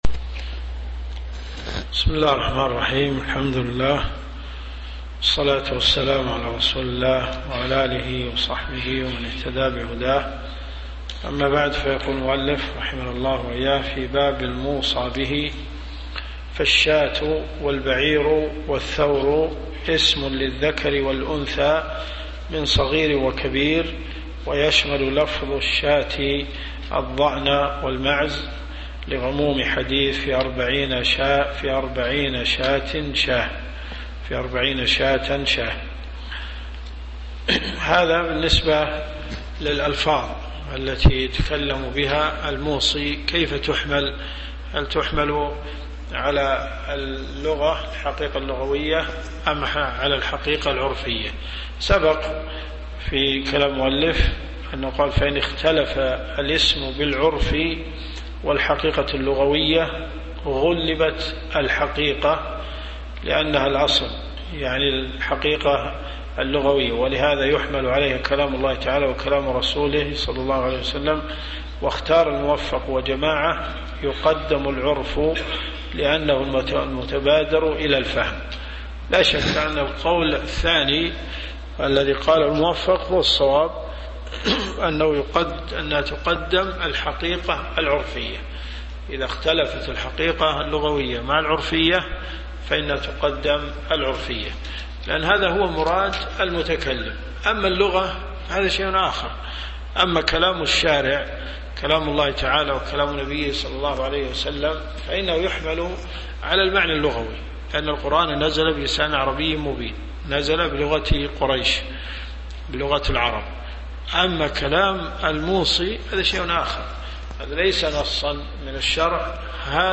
الرئيسية الكتب المسموعة [ قسم الفقه ] > منار السبيل .